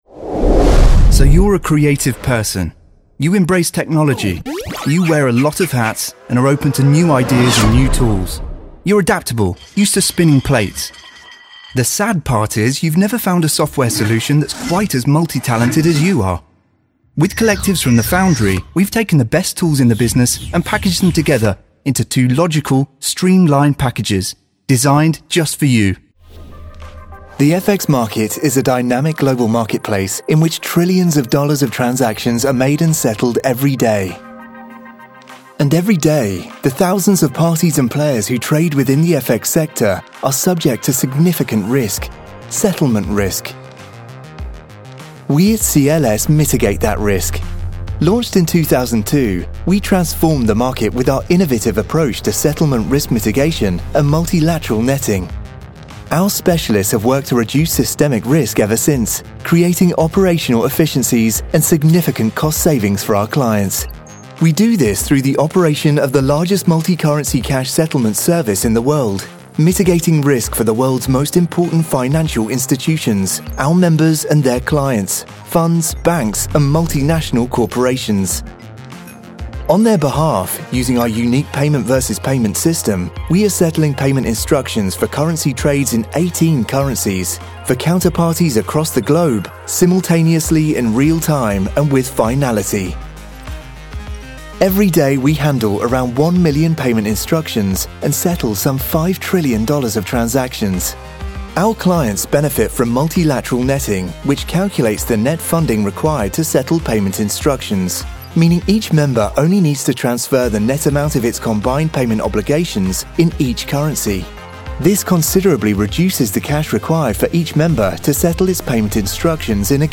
Listen - Best British Voiceover
Corporate Voice Reel
Corporate-voice-reel.mp3